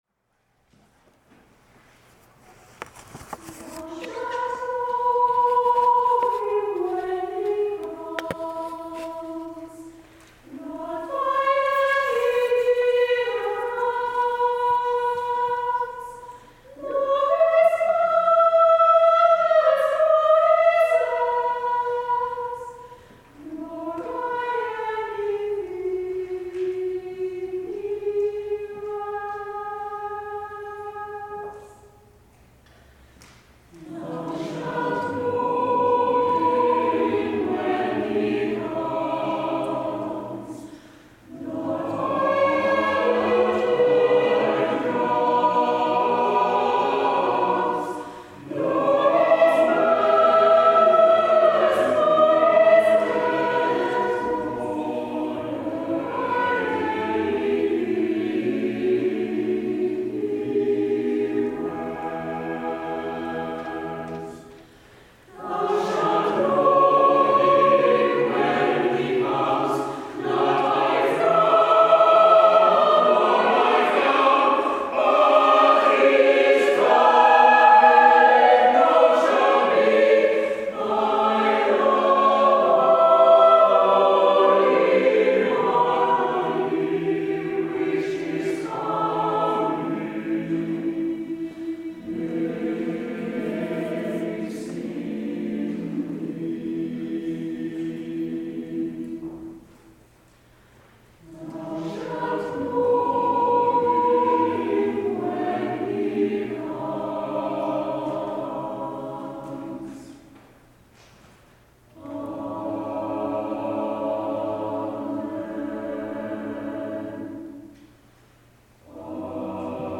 Thou shalt know him Choir of St. Andrew's Church